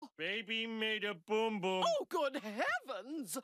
the-simpsons-baby-homer-audiotrimmer.mp3